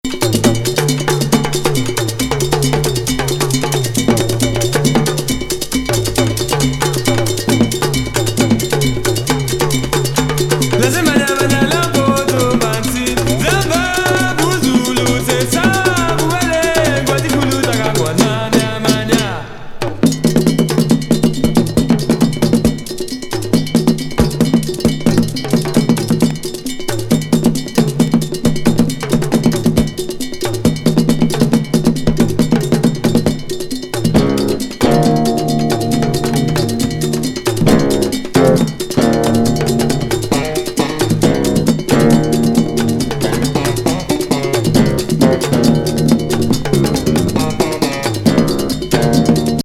ドイツ産本格派アフロ・キューバン・ジャズ!パーカッション・グルーヴB1